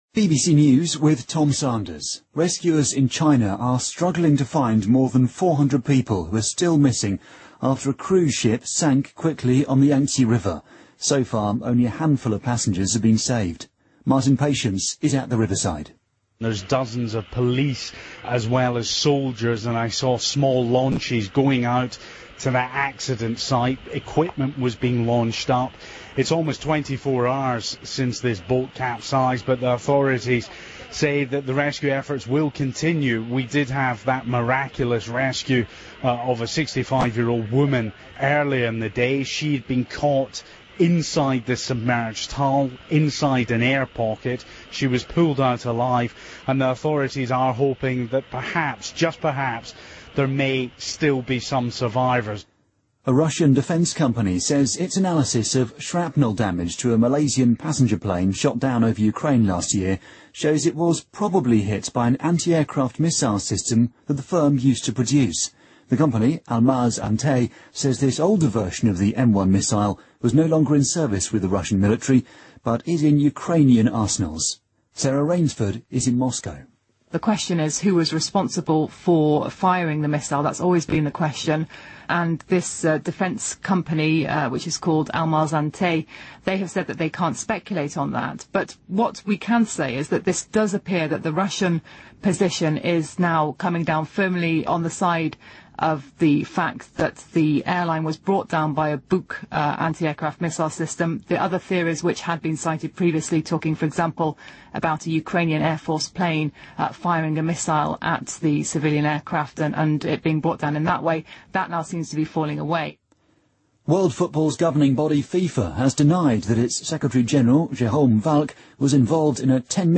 BBC news,中国救援人员仍在搜寻沉船失踪人员
日期:2015-06-04来源:BBC新闻听力 编辑:给力英语BBC频道